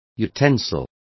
Complete with pronunciation of the translation of utensils.